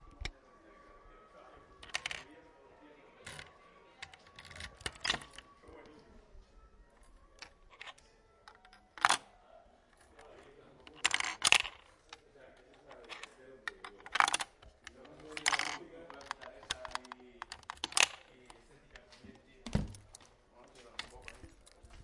储物柜
描述：ESMUC储物柜的声音